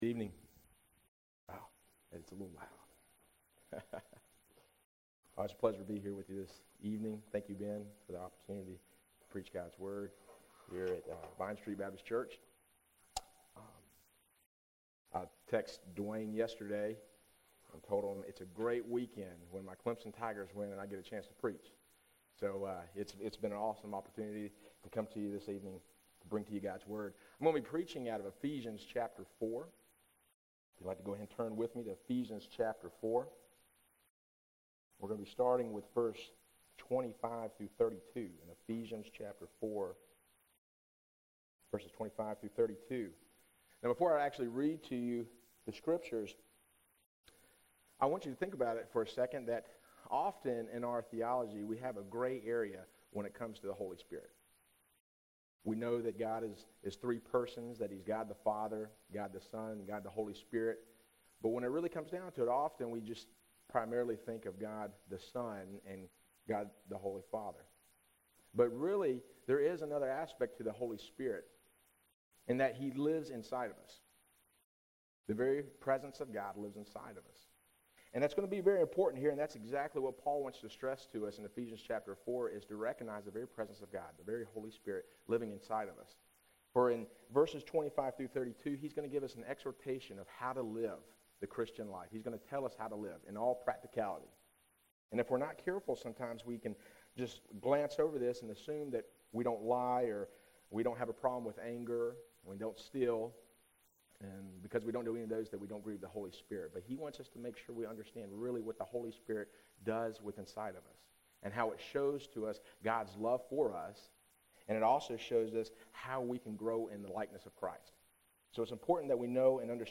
September 18, 2011 PM Worship | Vine Street Baptist Church